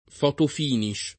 fotofinish [ f q tof & niš ]